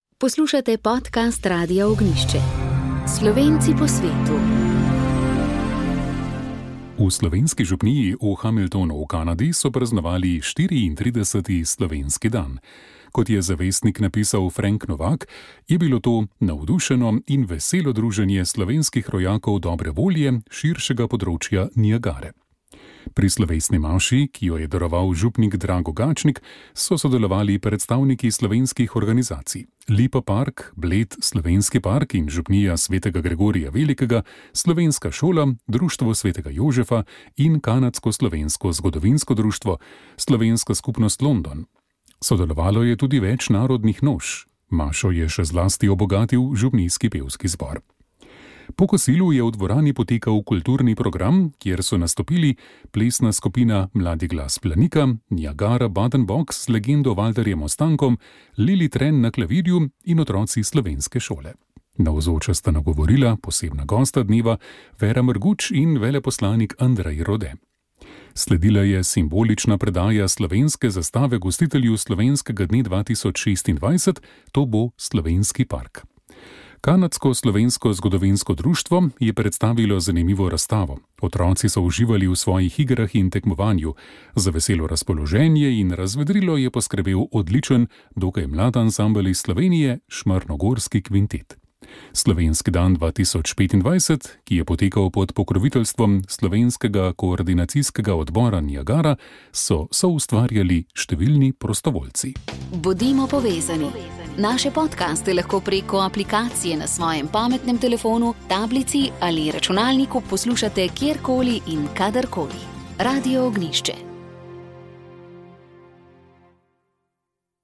Kako bo zakon o psihoterapiji, ki je trenutno v parlamentarnem postopku, vplival na dostopnost, kakovost in varnost psihoterapevtskih storitev v Sloveniji? Kdo bo smel izvajati psihoterapijo v zdravstvu in kako bo zagotovljeno varstvo pacientov? V oddaji Pogovor o smo ta in druga vprašanja zastavili sogovornikom v studiu